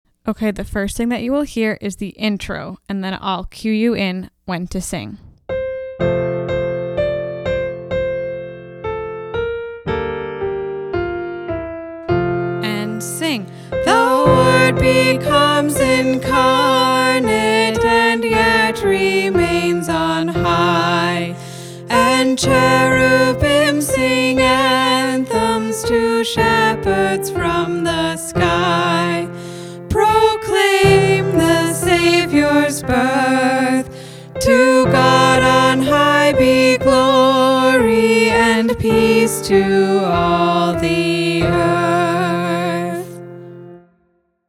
The Most Wonderful Time of the Year practice track A Great and Mighty Wonder- all The Word becomes incarnate and yet remains on high, and cherubim sing anthems to shepherds from the sky.